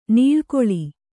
♪ nīḷkoḷi